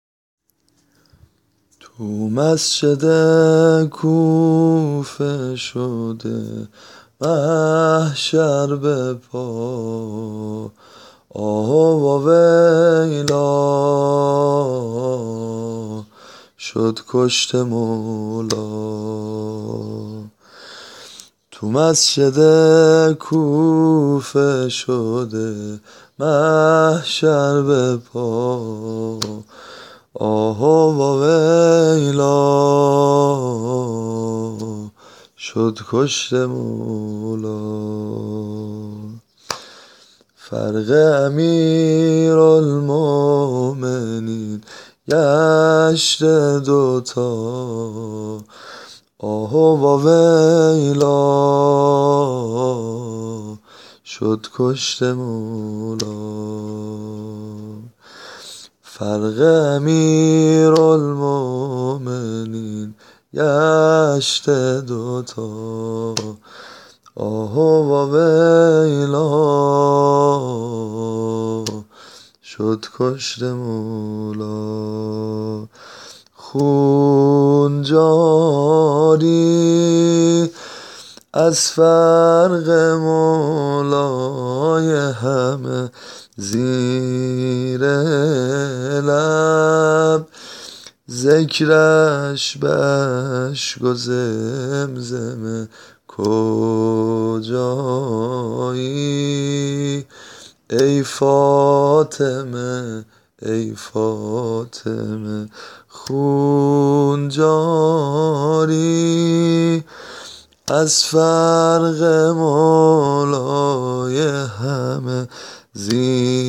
نوحه سنتی - شب نوزدهم ماه مبارک رمضان